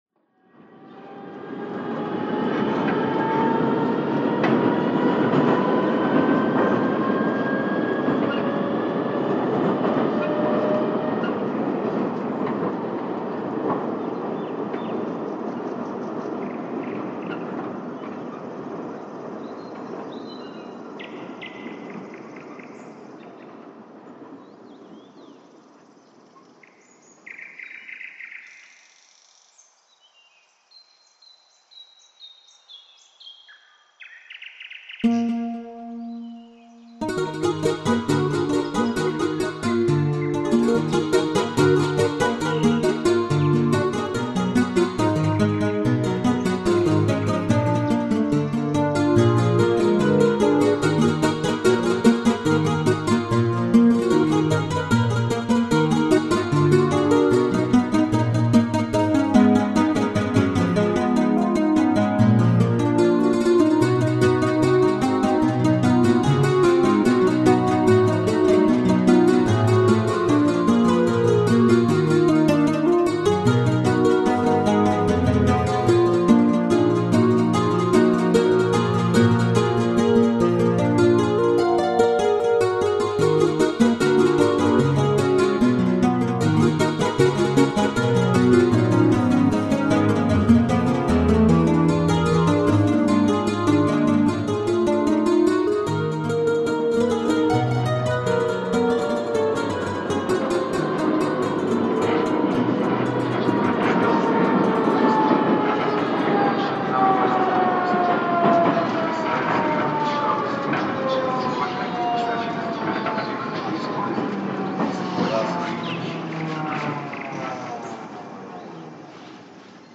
Nightingales and...
Time for the songs of nightingales, all night long.